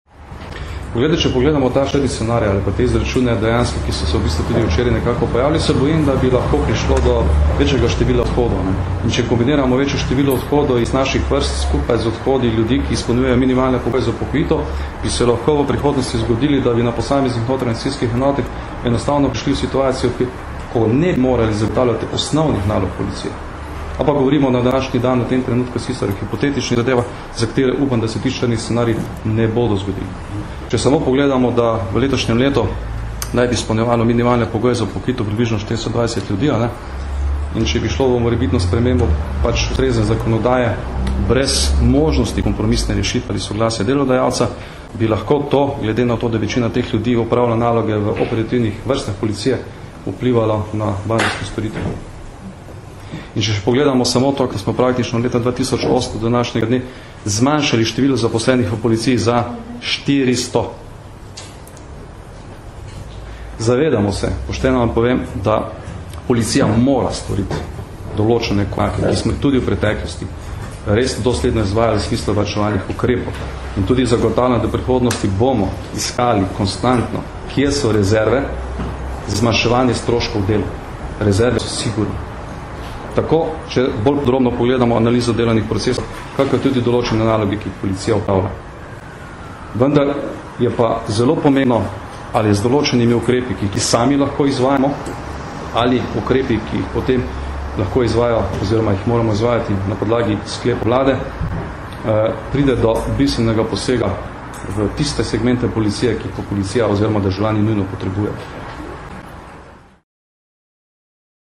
Kot je na današnji tiskovni konferenci povedal generalni direktor policije Janko Goršek, policija kot organ nima neposredne možnosti vplivati na odločitev posameznikov o upokojitvi, če ti izpolnjujejo pogoje zanjo.
Zvočni posnetek izjave Janka Gorška (mp3)